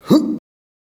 jump (2).wav